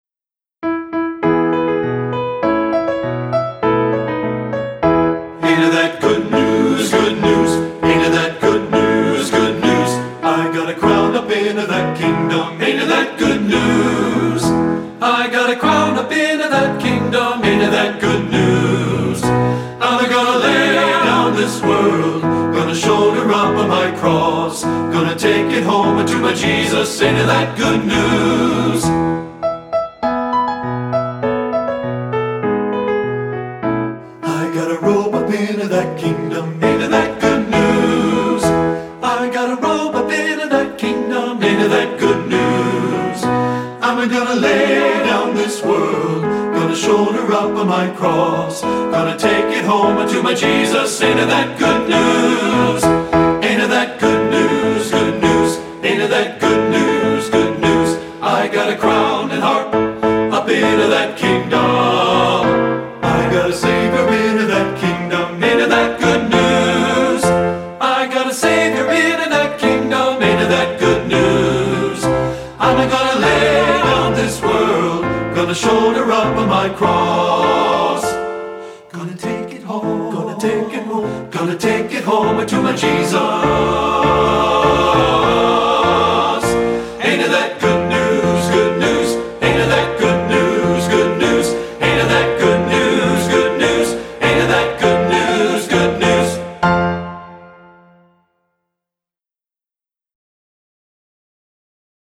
Composer: African-American Spiritual
Voicing: TBB and Piano